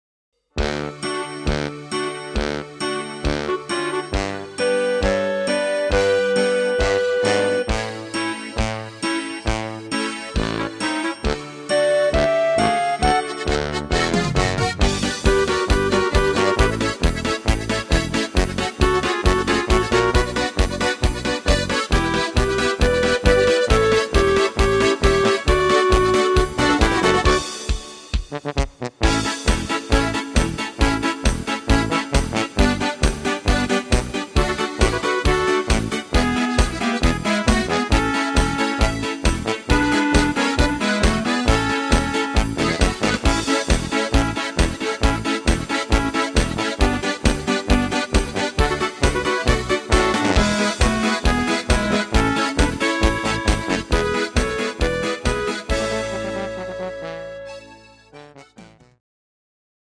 Oberkrainer Sound